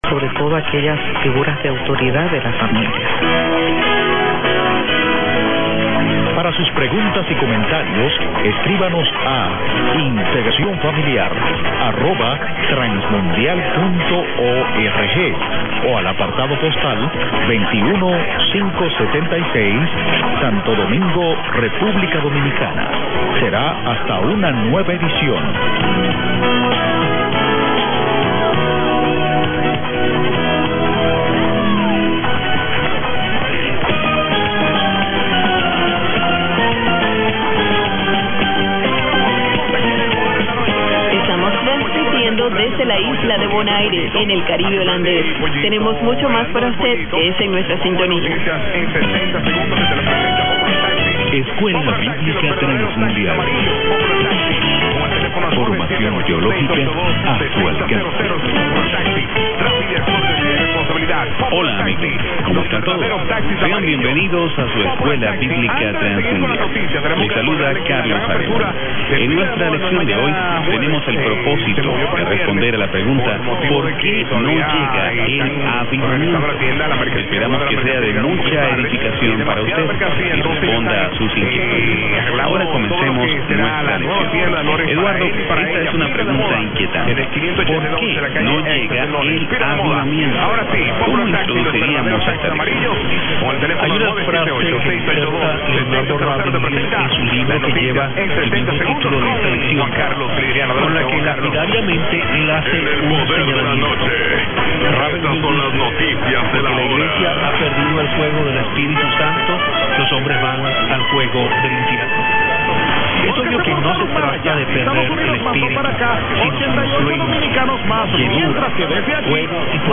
One is PJB (Radio Transmundial) which dominates at the beginning of the audio clip.  The second station starts gaining and is better towards the end of the clip.  It might just be WNNW Lawrence, MA but possibly something of Latin American origin instead.